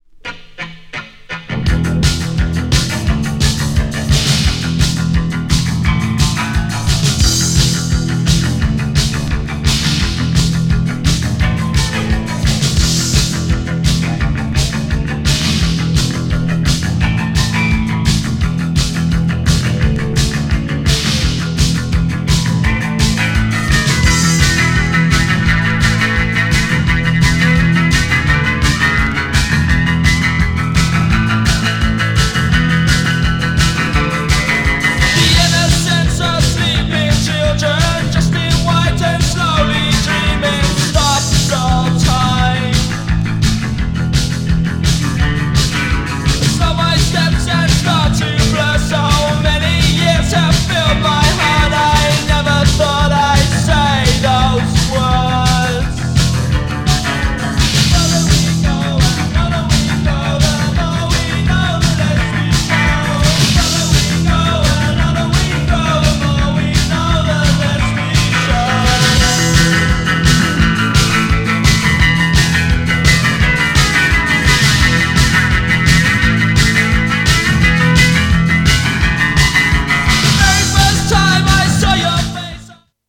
GENRE Dance Classic
BPM 151〜155BPM